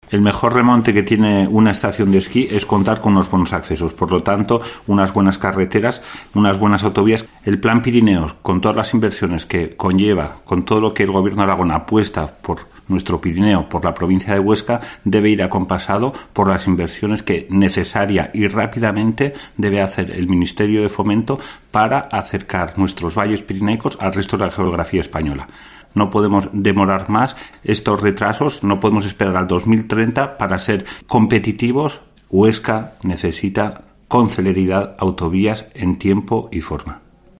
Jesús Gericó, alcalde de Sallent de Gállego y diputado provincial